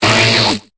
Cri de Ninjask dans Pokémon Épée et Bouclier.